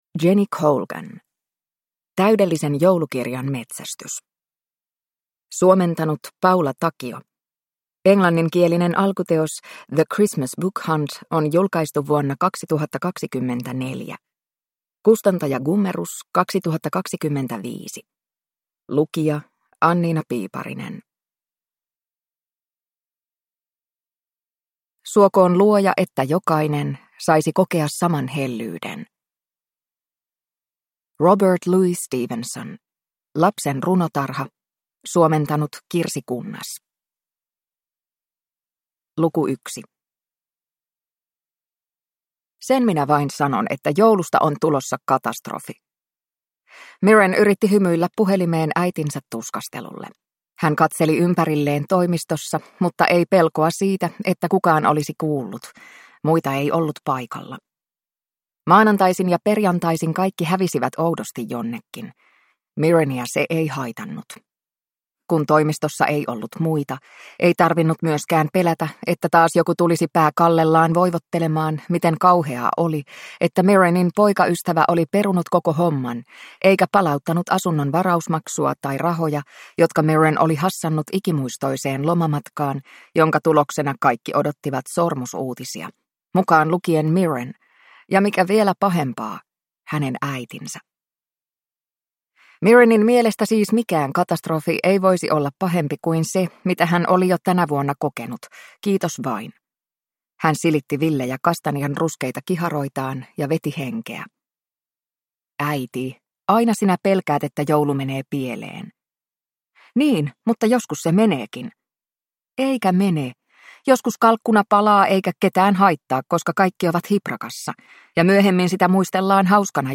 Täydellisen joulukirjan metsästys (ljudbok) av Jenny Colgan